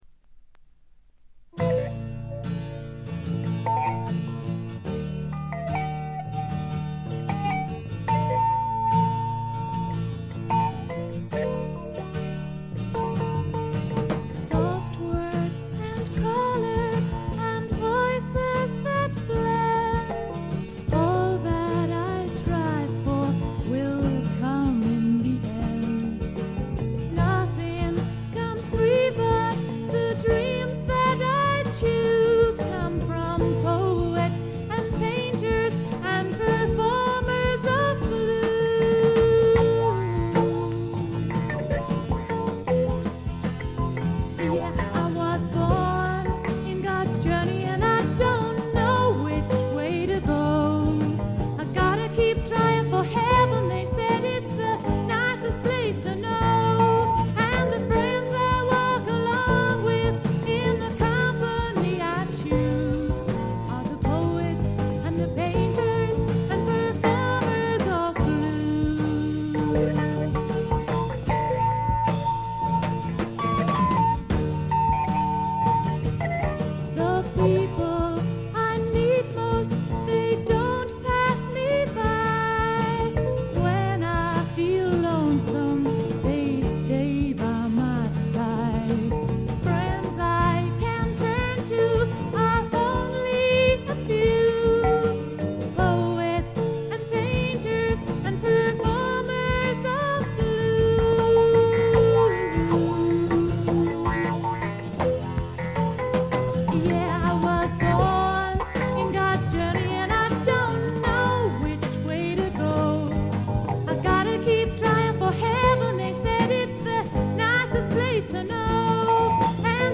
曲はすべて自作で、いかにも英国の女性シンガー・ソングライターらしいポップながら陰影のある曲が並びます。
試聴曲は現品からの取り込み音源です。
vocal, acoustic guitar